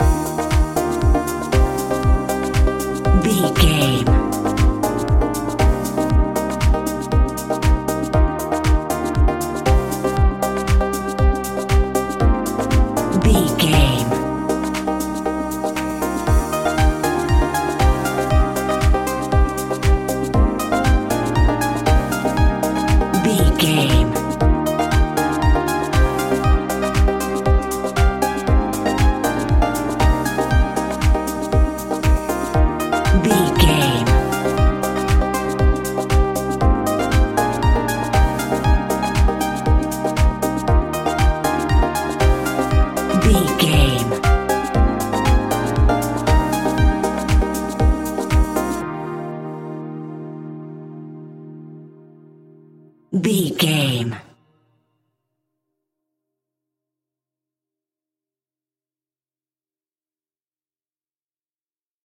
royalty free music
Ionian/Major
E♭
uplifting
energetic
bouncy
electric piano
drum machine
synthesiser
electro house
progressive house
synth leads
synth bass